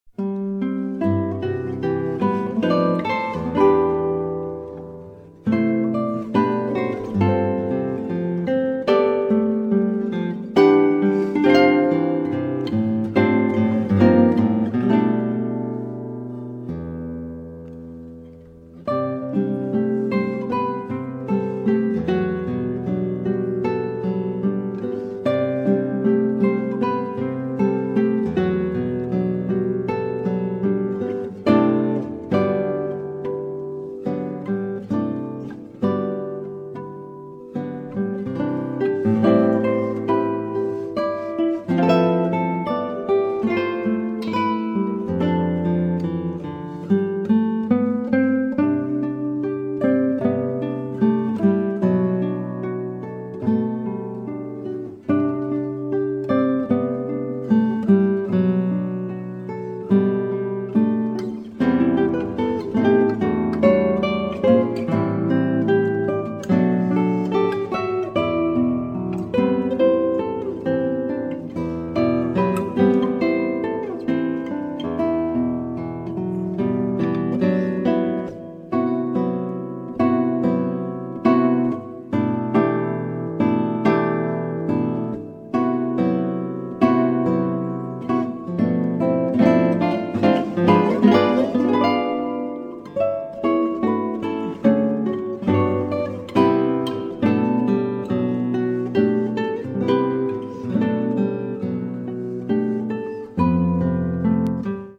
鮮烈かつ甘く響くギターが絶品です！
強力なエネルギーを放つかの如く発せられる瑞々しいギター・プレイが極上の味わいを産み出しています！